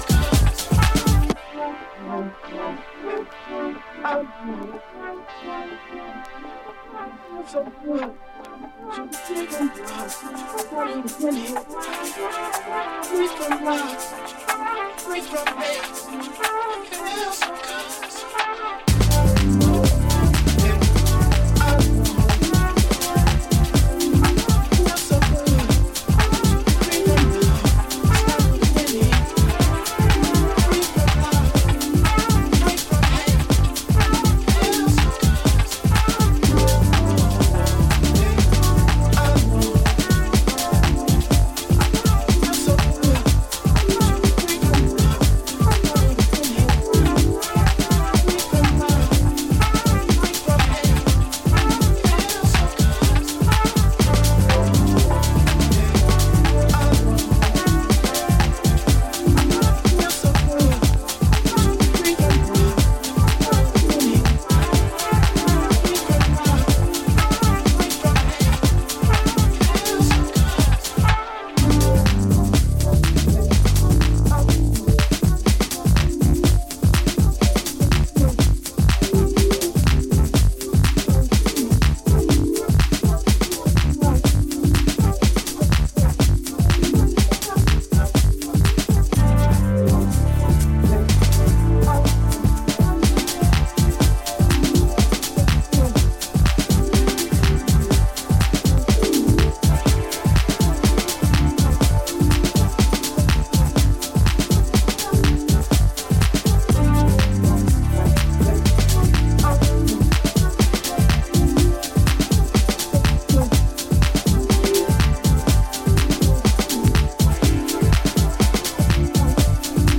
スムースかつブロークンなリズムが躍動しニュージャズ方面の音好きにもおすすめしたい
いずれもジャジーでオーセンティックな魅力を秘めた